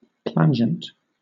Ääntäminen
Southern England: IPA : /ˈplænd͡ʒənt/